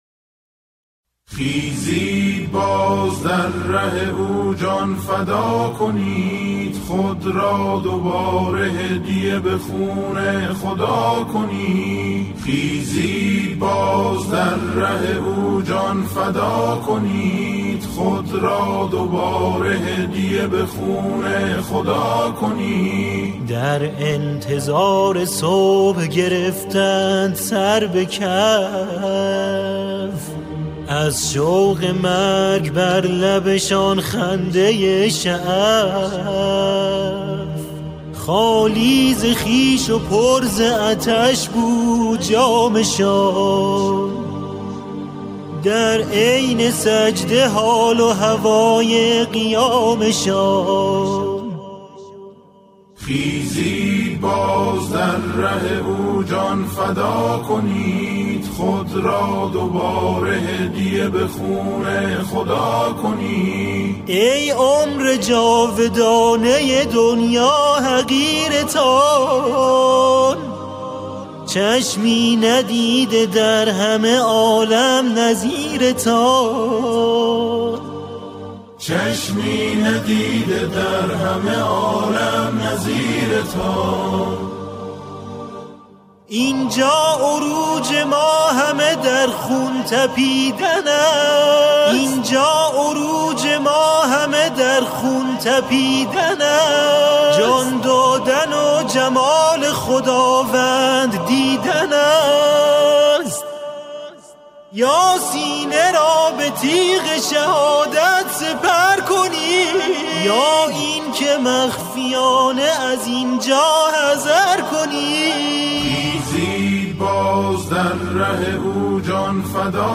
سرودهای امام حسین علیه السلام
همراهی گروهی از جمعخوانان